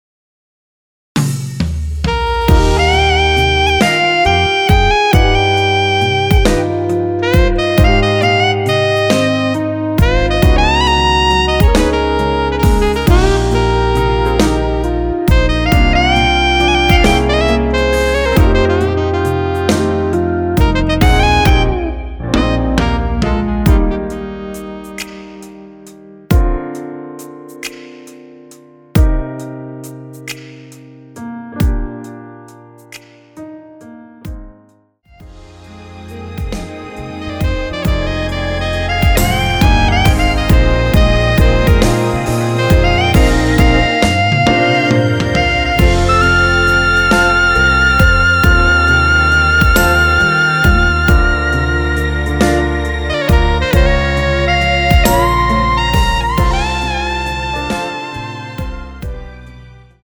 원키에서(+4)올린 MR입니다.
Eb
앞부분30초, 뒷부분30초씩 편집해서 올려 드리고 있습니다.
중간에 음이 끈어지고 다시 나오는 이유는